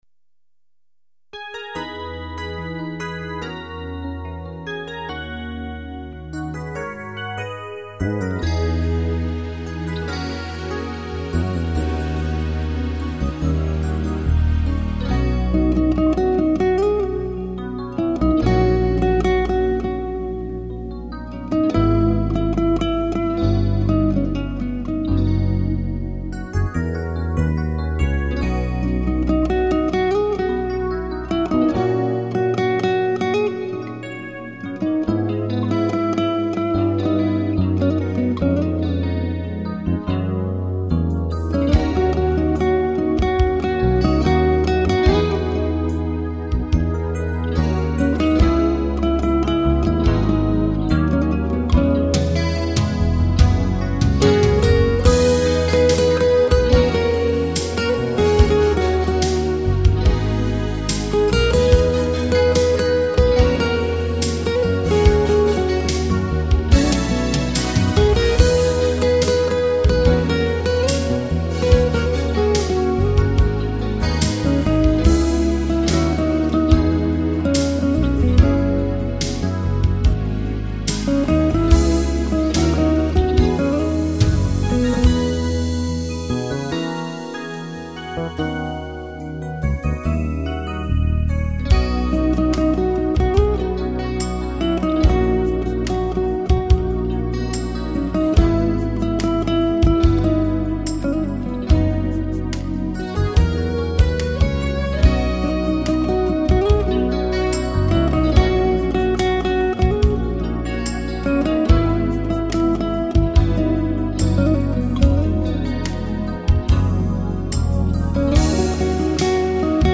[欧美音乐]
经典名曲 百听不厌 熟悉的旋律 优美的音乐 勾起我们绵绵依旧之情......